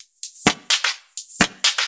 Index of /VEE/VEE2 Loops 128BPM
VEE2 Electro Loop 151.wav